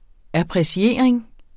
Udtale [ apʁeˈɕeɐ̯ˀeŋ ]